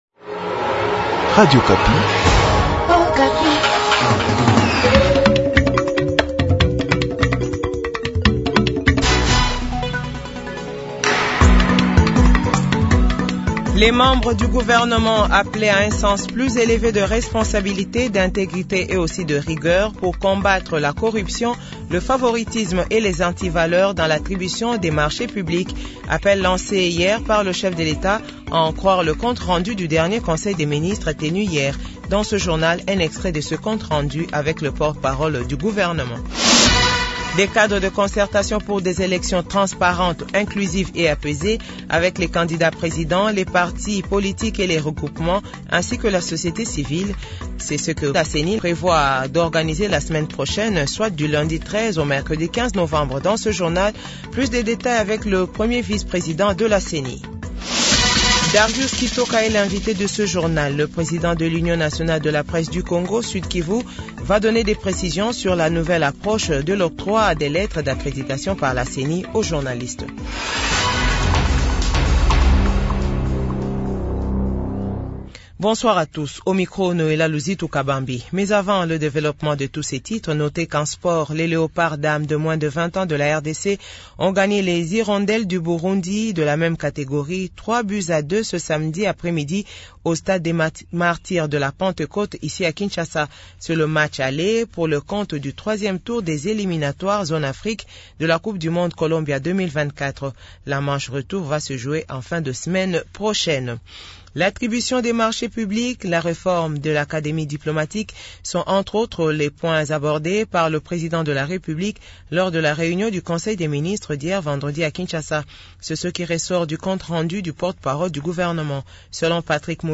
Journal de 18H00